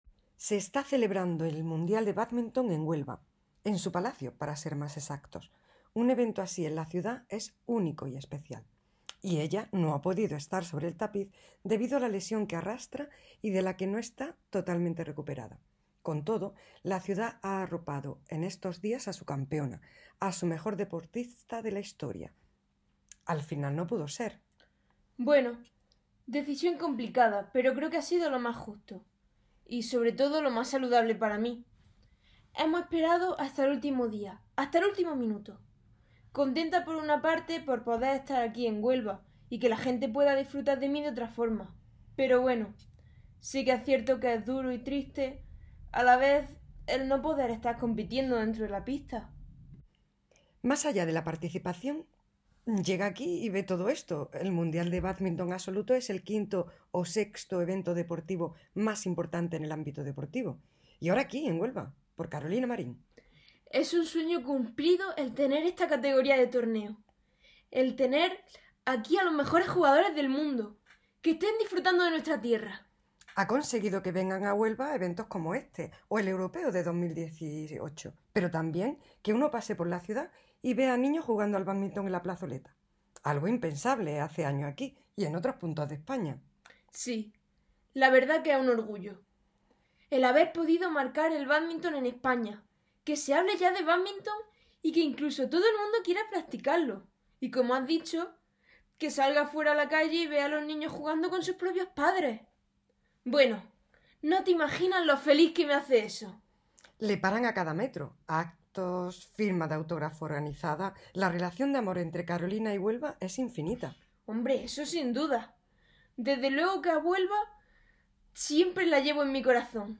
Entrevista a Carolina Marín
Carolina-Marin-entr.mp3